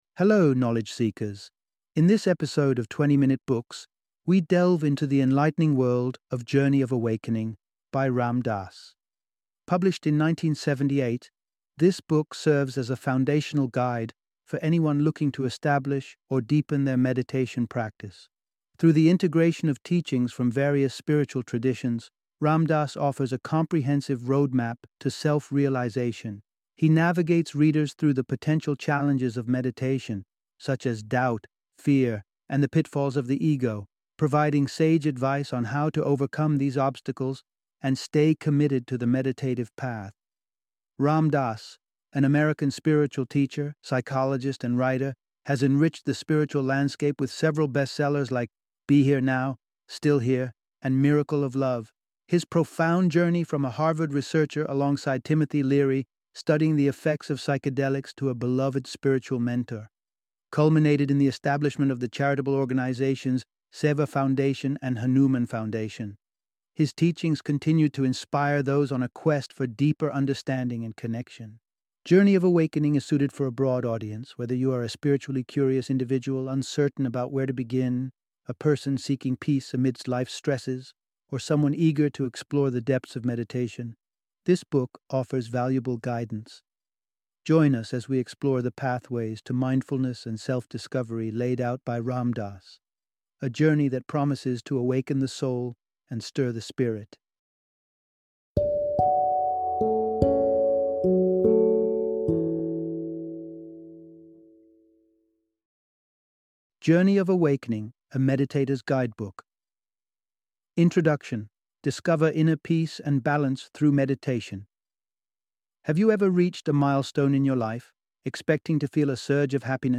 Journey of Awakening - Book Summary